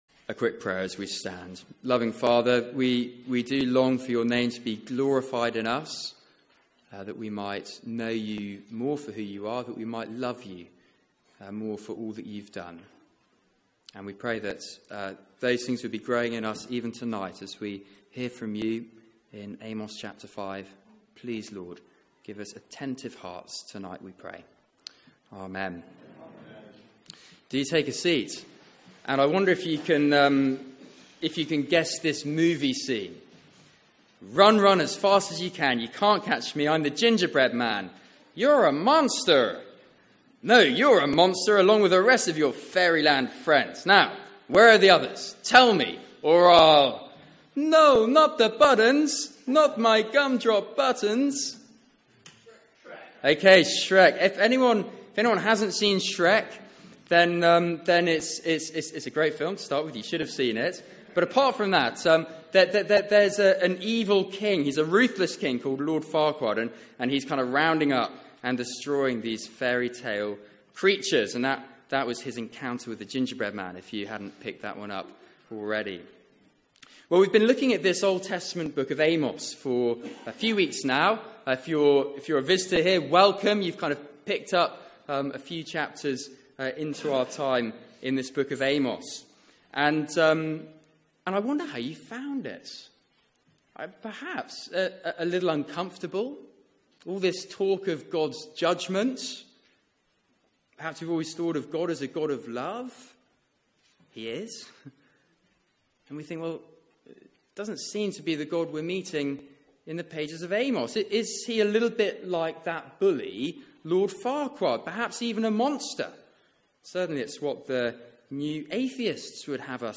Media for 6:30pm Service on Sun 26th Feb 2017 18:30 Speaker
Series: Prepare to meet your God Theme: Your God calls you back Sermon